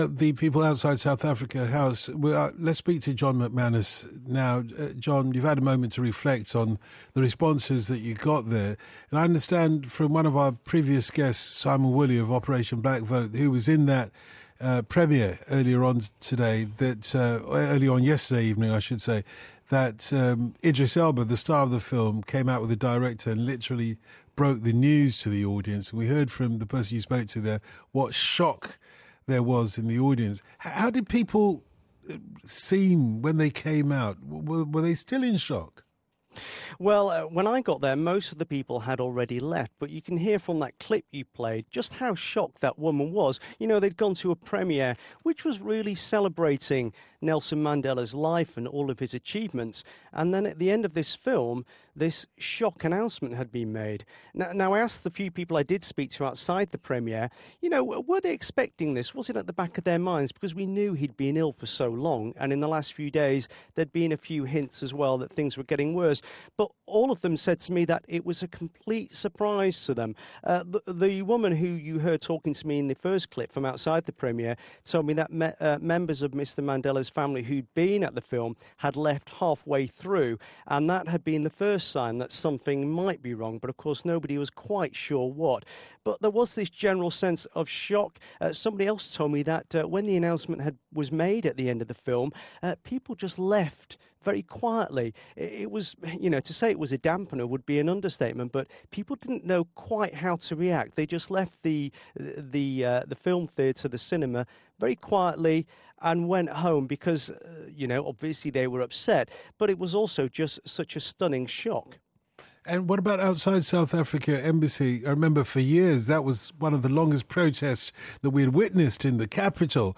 2way for 5live - reaction in London to Mandela death